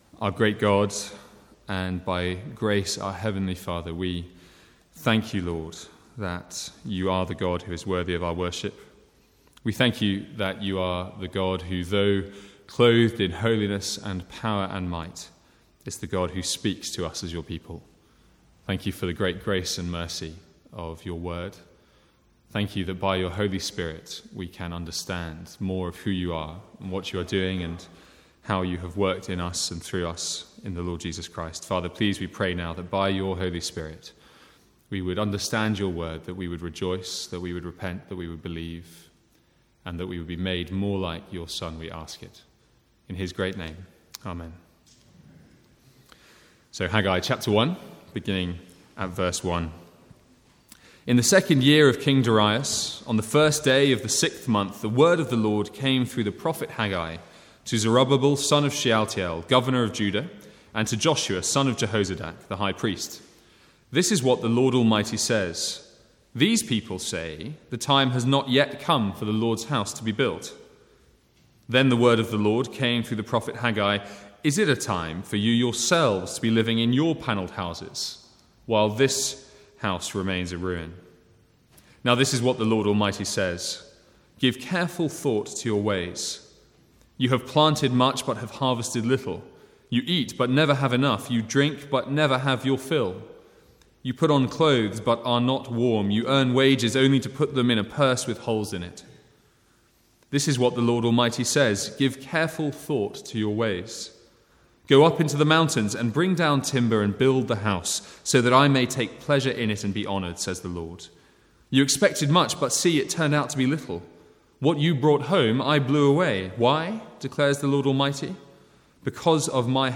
From the Sunday morning series in Haggai.